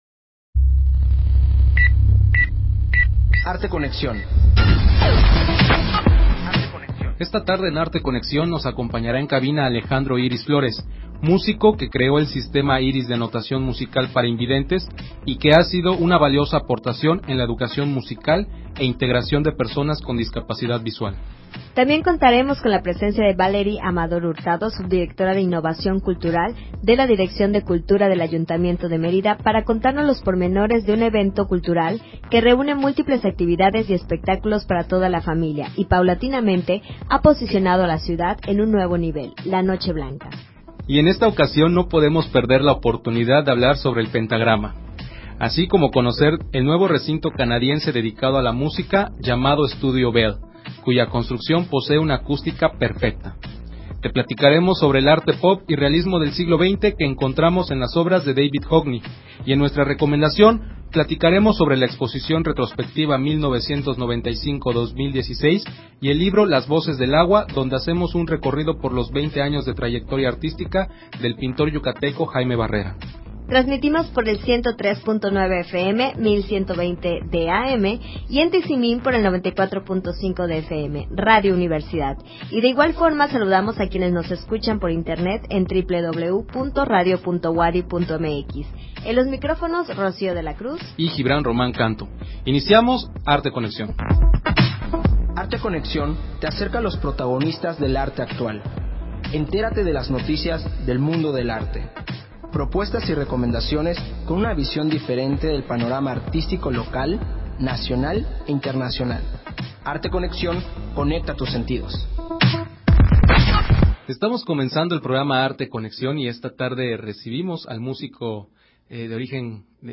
Emisión de Arte Conexión transmitida el 8 de diciembre de 2016.